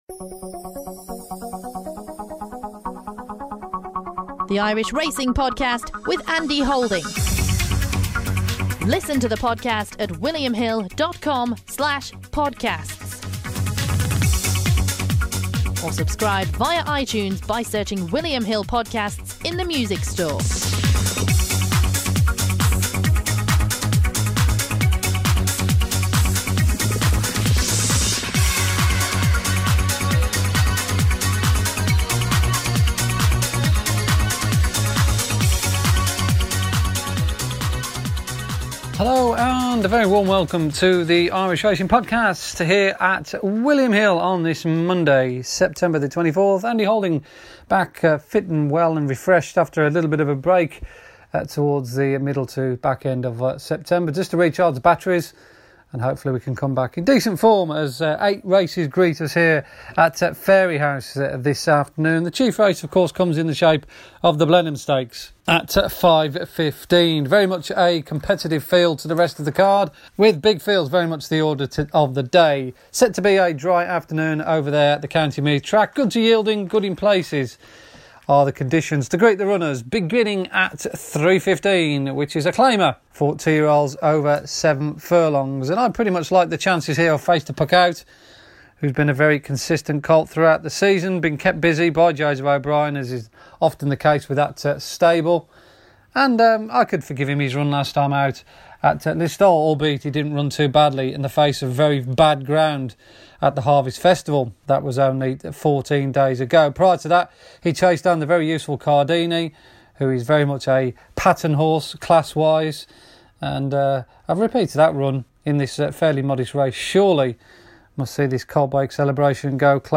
Apologies for the sound quality in certain parts of the podcast.